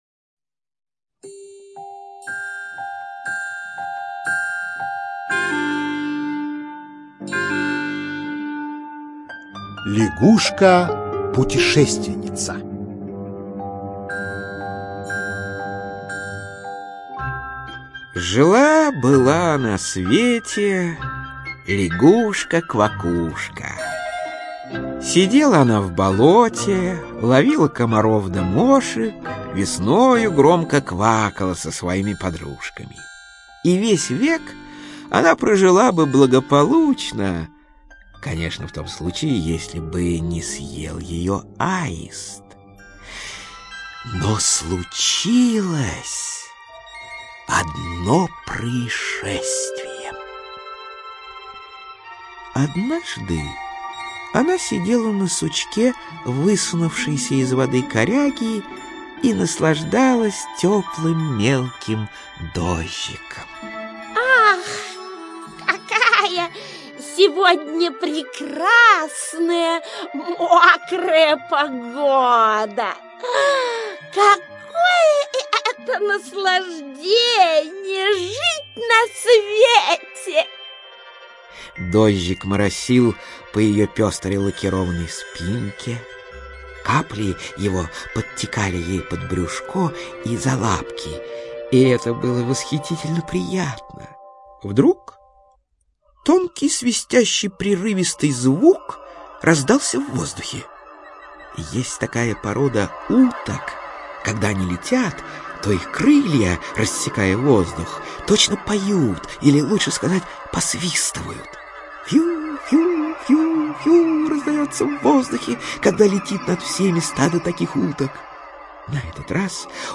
Лягушка-путешественница - аудиосказка Гаршина - слушать онлайн